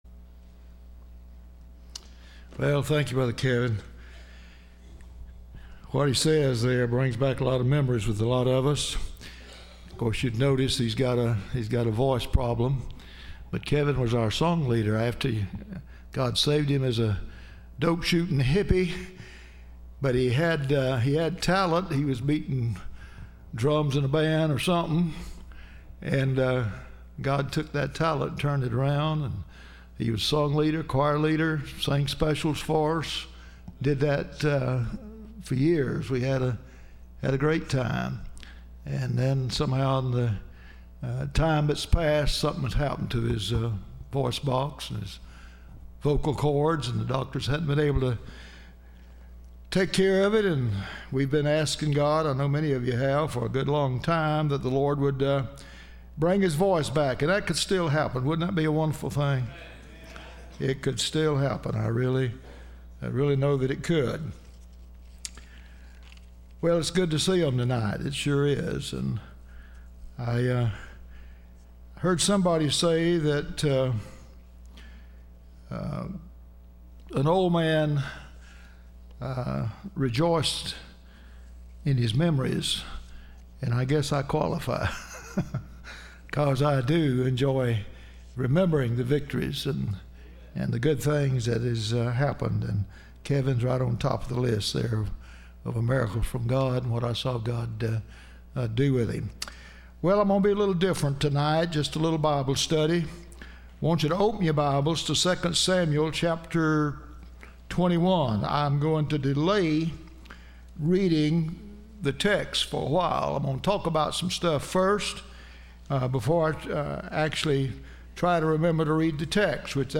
Service Type: Wednesday
Listen to Message